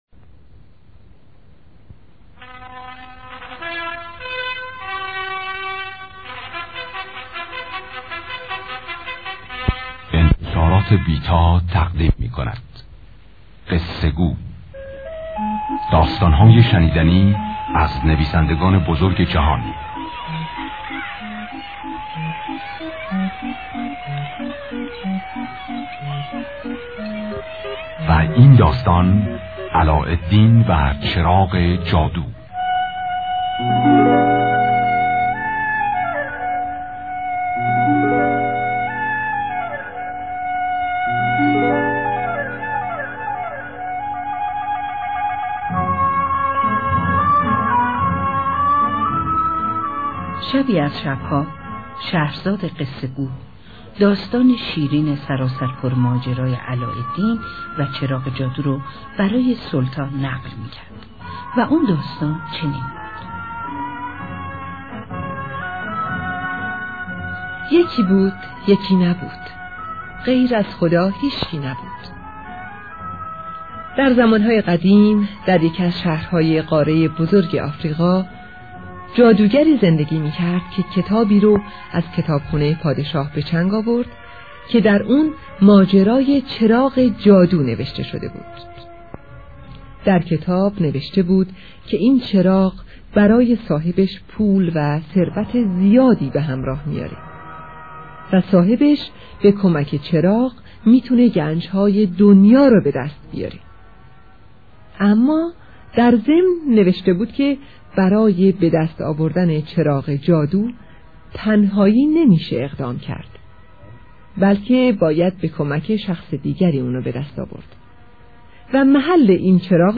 داستان صوتی و موزیکال کودکانه علاالدین با فرمت mp3